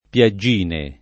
[ p L a JJ& ne ]